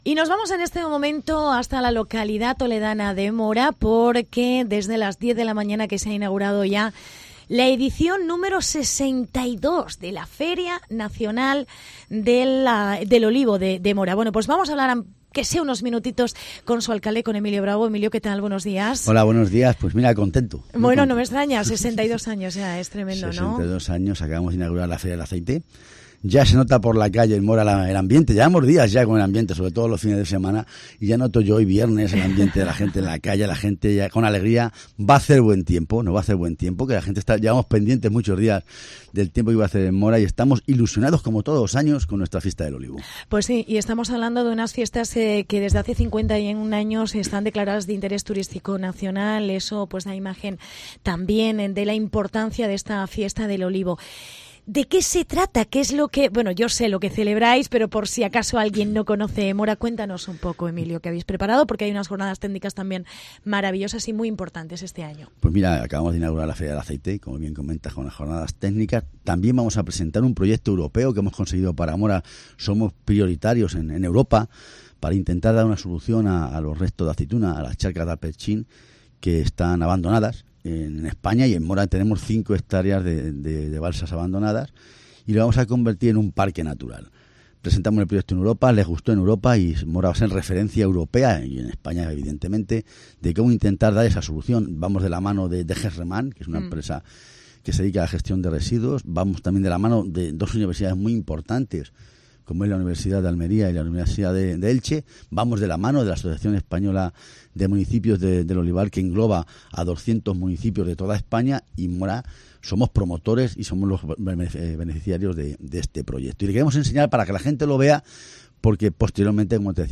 Entrevista a su alcalde: Emilio Bravo